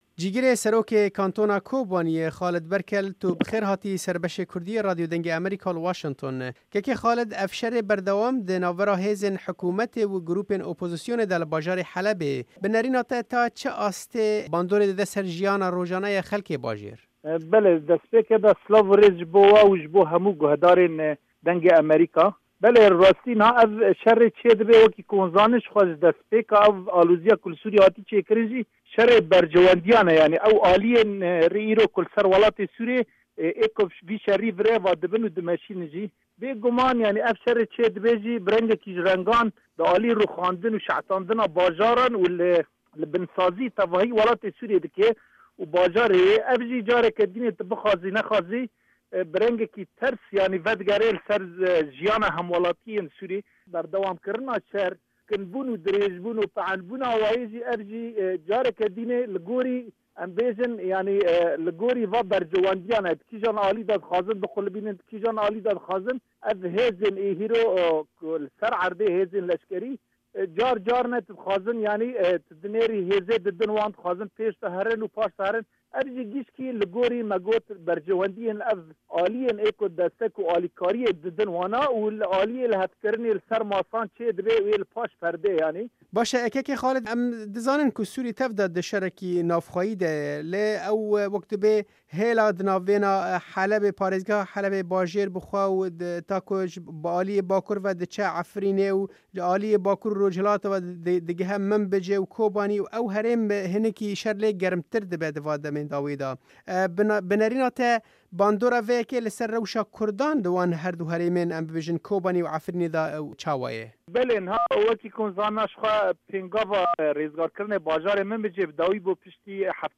Cîgirê serokê Kantona Kobaniyê Xalid Berkel di hevpeyvînekê de ligel Dengê Amerîka rewşa şer li Heleb û Minbicê şîrove kir û got: